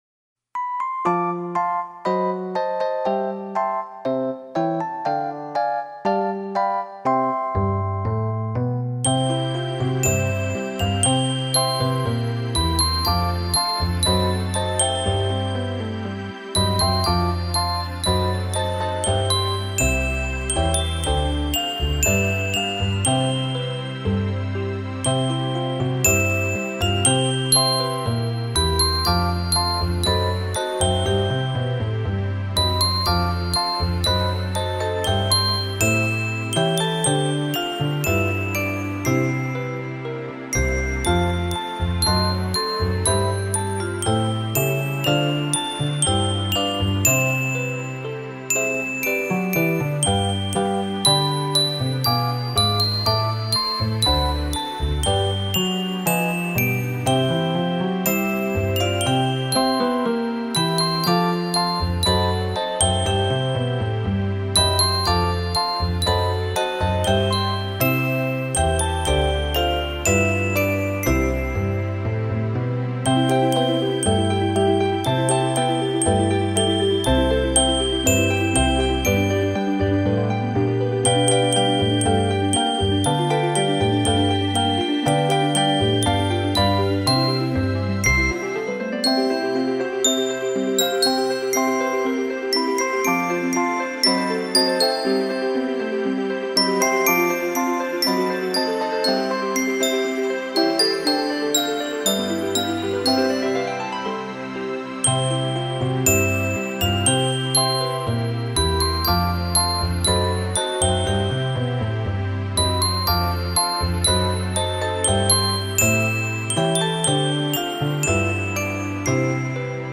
水晶青脆的聲音